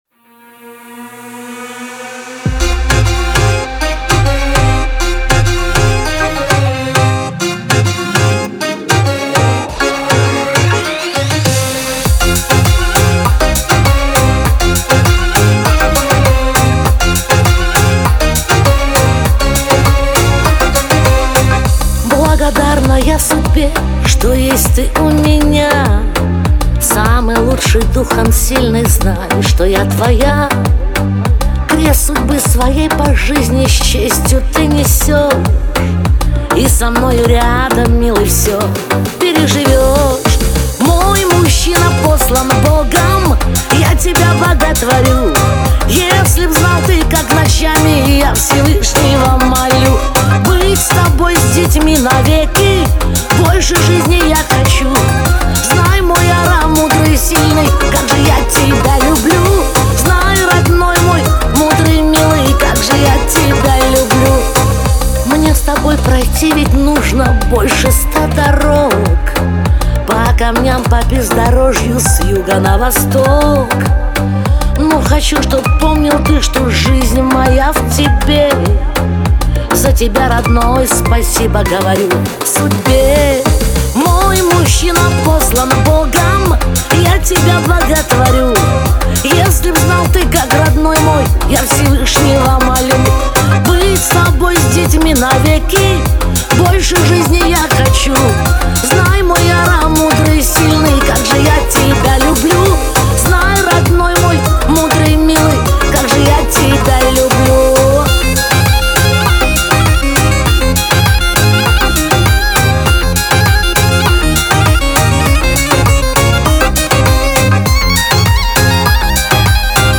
Звучание песни отличается мелодичностью и теплым вокалом